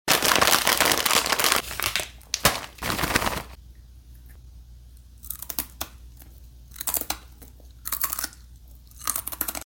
Wasabi Lotus Root Chips 🍟 Sound Effects Free Download